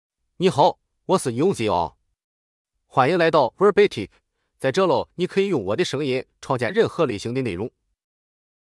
Yunxiang — Male Chinese (Jilu Mandarin, Simplified) AI Voice | TTS, Voice Cloning & Video | Verbatik AI
Yunxiang is a male AI voice for Chinese (Jilu Mandarin, Simplified).
Voice sample
Listen to Yunxiang's male Chinese voice.
Male